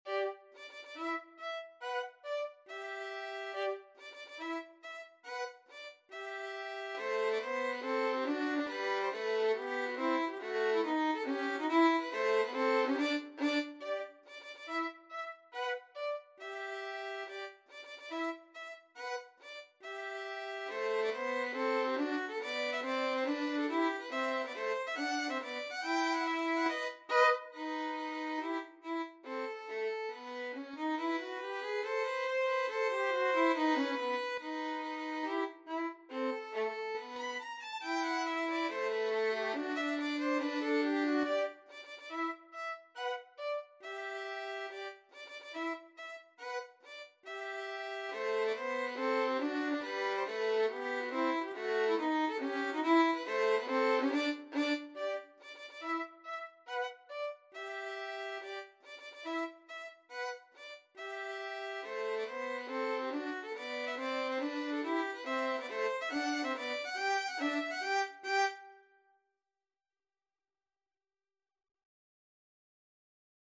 4/4 (View more 4/4 Music)
Tempo di marcia =140
Classical (View more Classical Violin Duet Music)